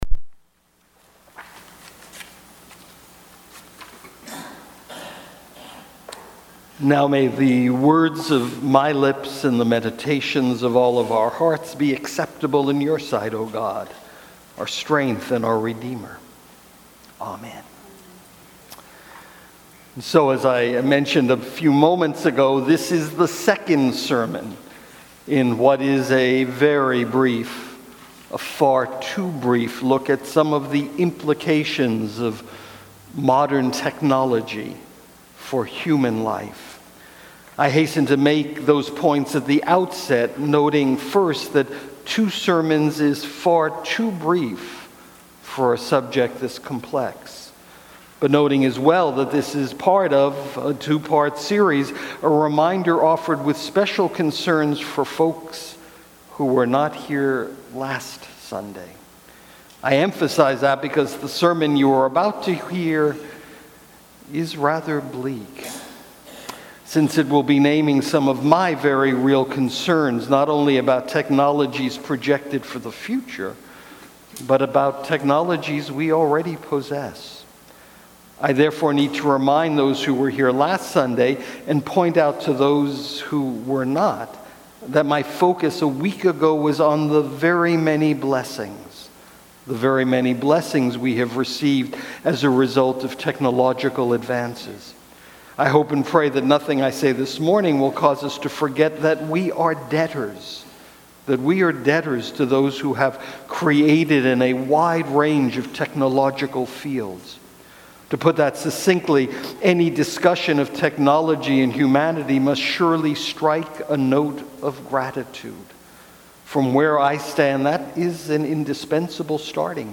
Sermons | Trinity United Church
Guest Preacher